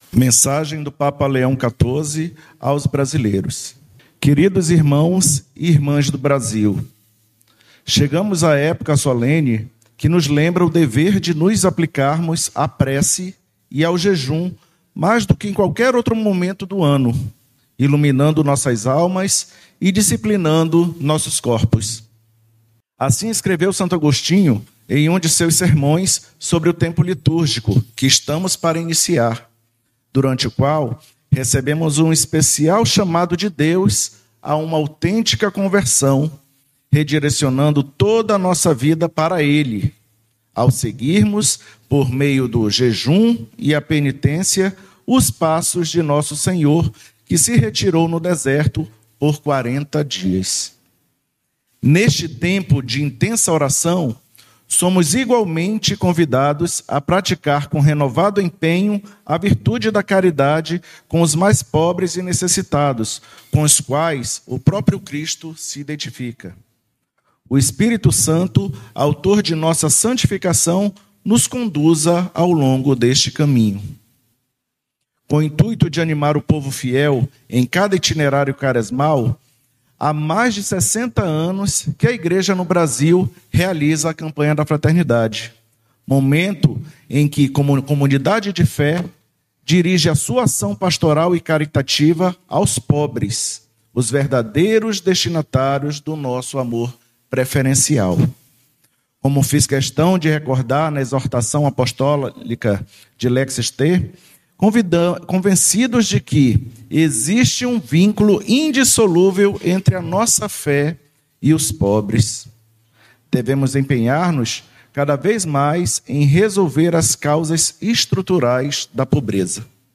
Ao final do encontro, os participantes acompanharam a mensagem do Papa Leão XIV aos brasileiros, em sintonia com a reflexão proposta pela Campanha da Fraternidade deste ano.